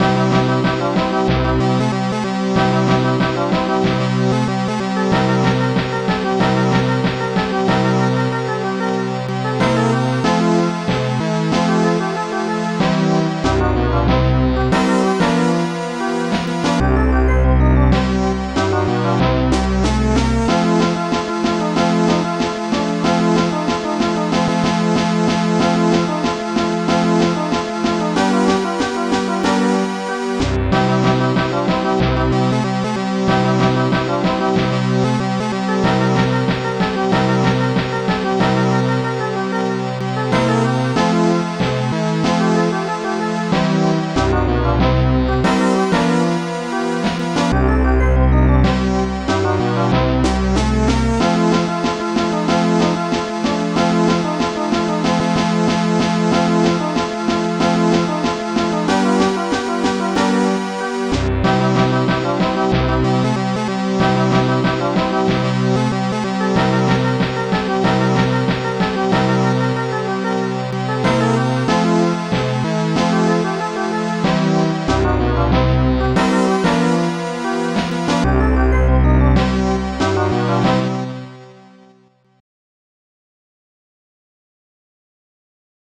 Piano
Stringed Backing
Snare drum
onverted from Archimedes Tracker